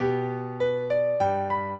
minuet12-4.wav